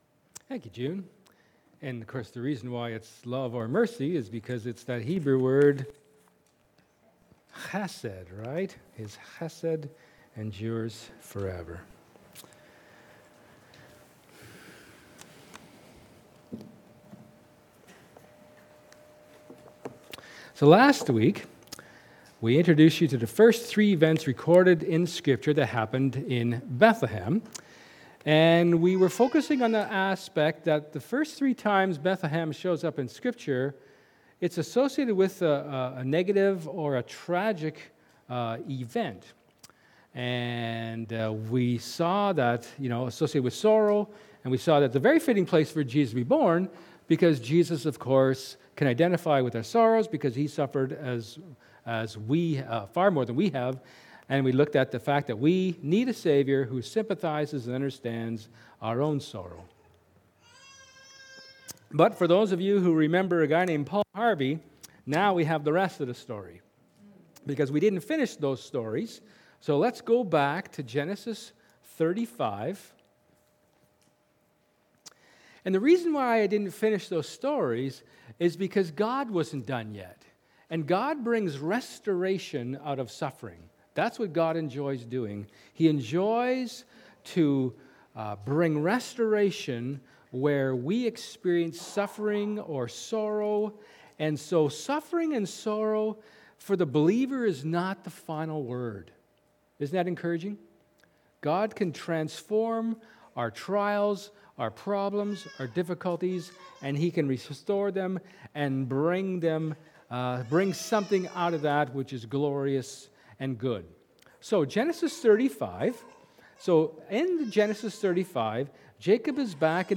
Luke 2:8-20 Service Type: Sermon